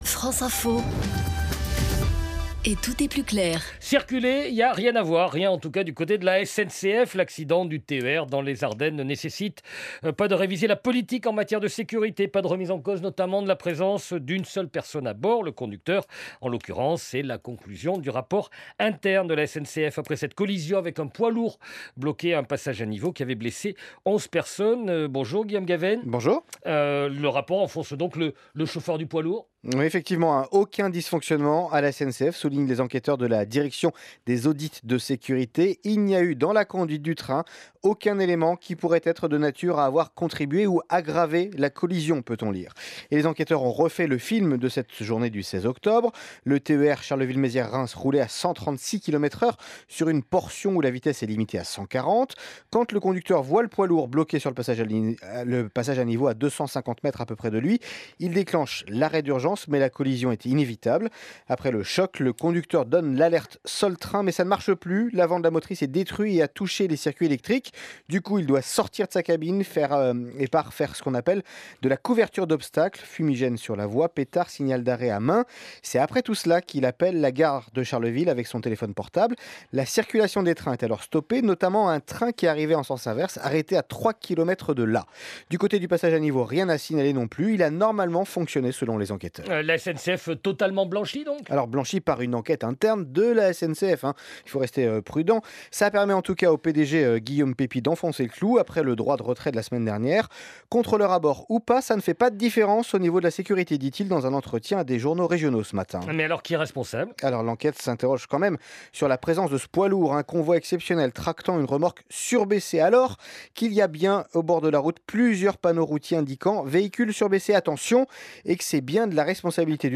Interview de France Info sur l’accident de Boulzicourt survenu le 16 octobre 2019